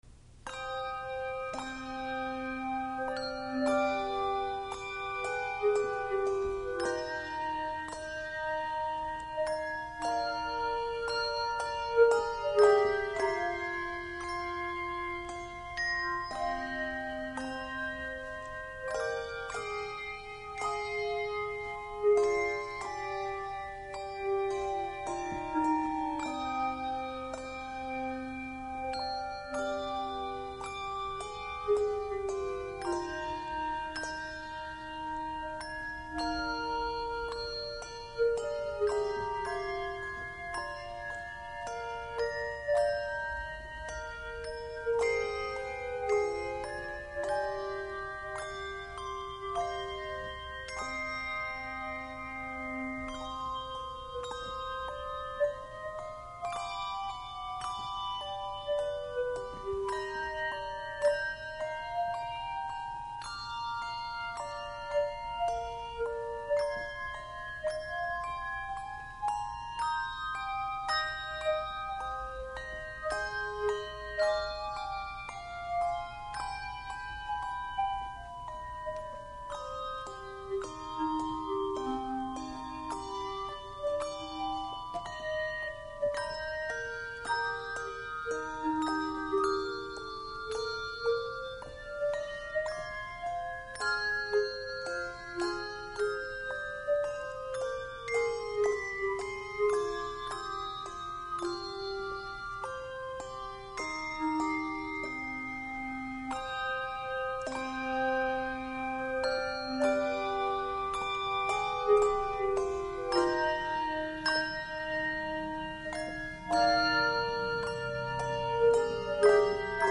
Handbell Quartet
A rich sound and a colorful offering.
No. Octaves 5 Octaves